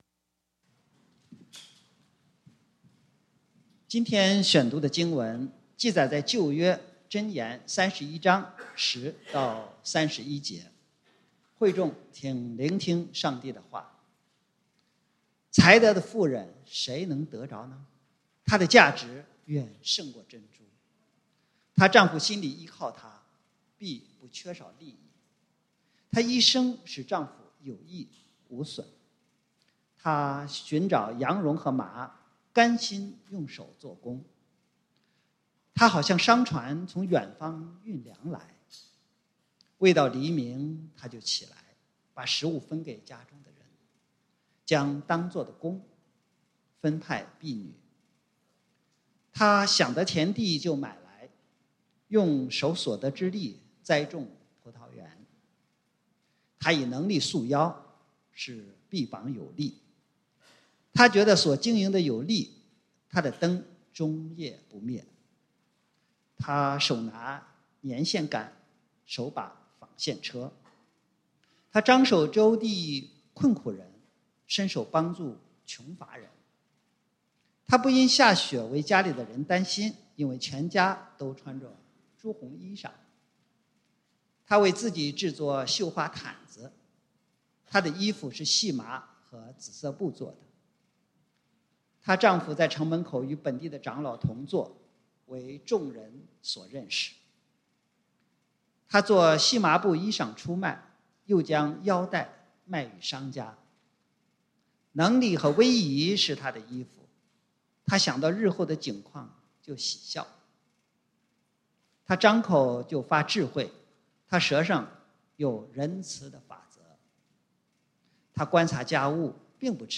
母親節見證分享 (經文：箴言31:10-31) | External Website | External Website